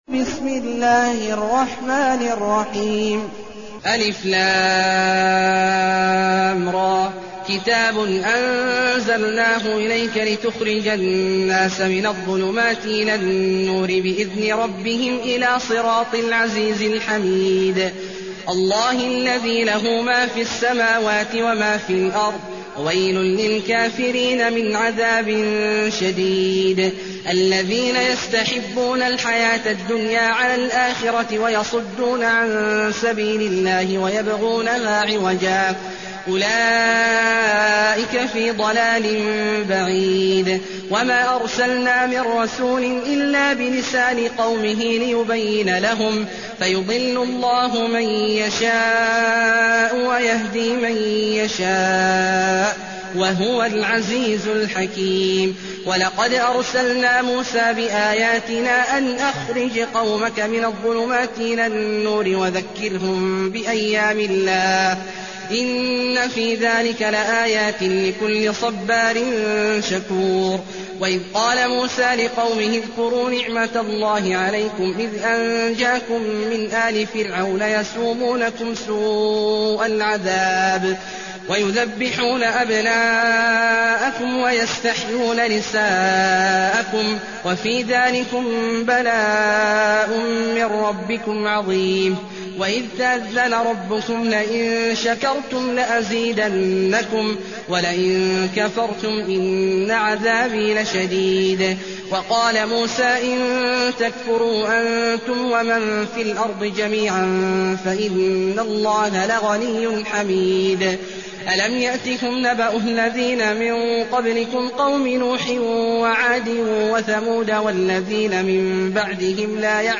المكان: المسجد النبوي الشيخ: فضيلة الشيخ عبدالله الجهني فضيلة الشيخ عبدالله الجهني إبراهيم